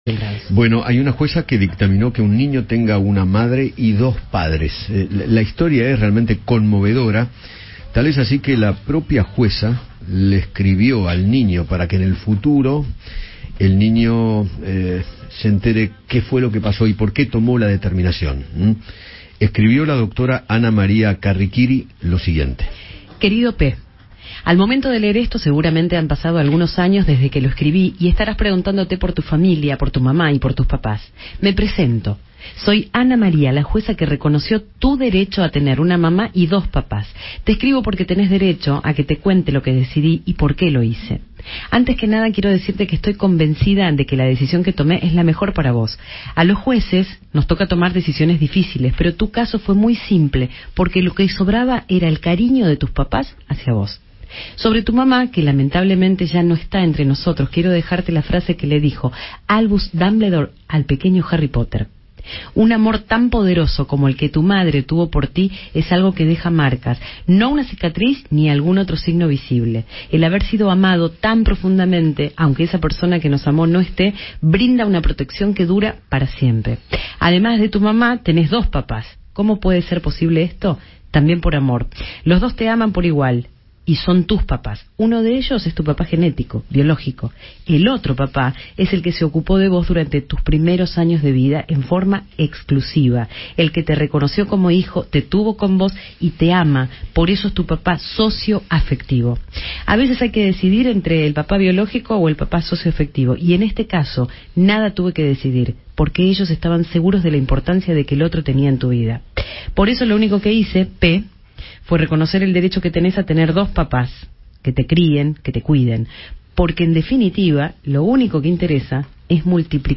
La Jueza Ana María Carriquiry conversó con Eduardo Feinmann sobre el fallo que dictaminó para que un niño tenga una madre y dos papás y detalló la carta que le escribió al joven para que entienda su sentencia.